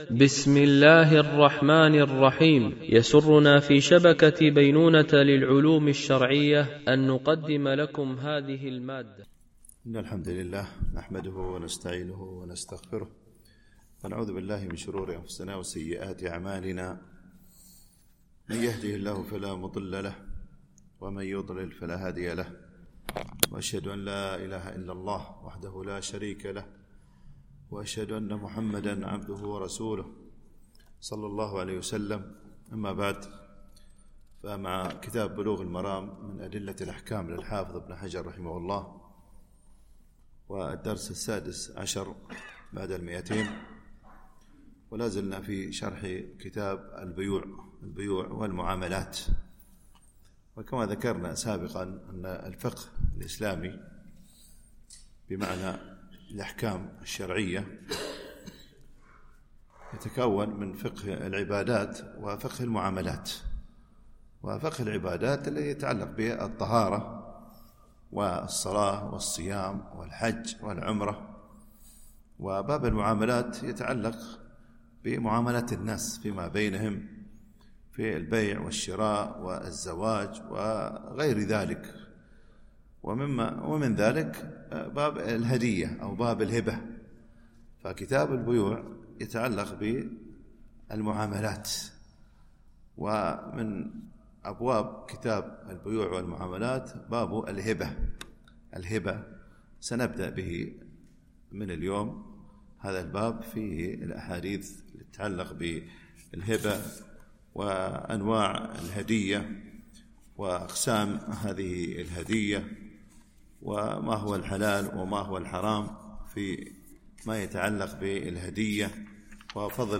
شرح بلوغ المرام من أدلة الأحكام - الدرس 216 ( كتاب البيوع - الجزء ٥٠ - الحديث 930 )